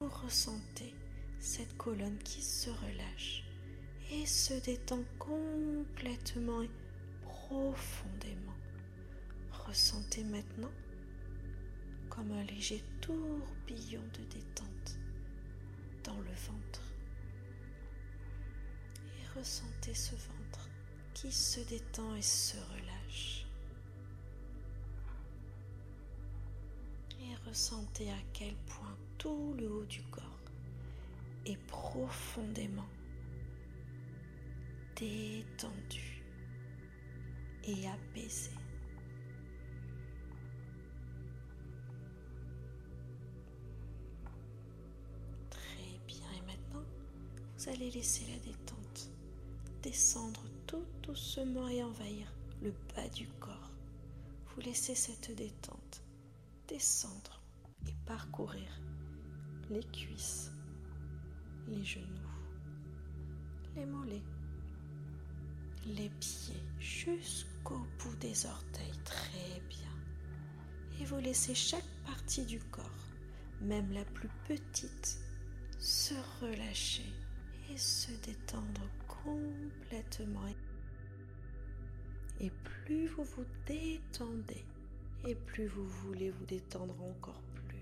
Cette séance, à écouter avec un casque audio, vous plongera dans un univers apaisant, où chaque son et chaque mot sont soigneusement choisis pour vous guider vers un état de relaxation optimal et la création de votre lieu refuge, lieu qui vous permet de vous ressourcer, de vous apaiser.
2. Introduction : La séance commence par une introduction douce, vous préparant à la relaxation.
Les voix apaisantes et les sons enveloppants vous aideront à vous laisser aller.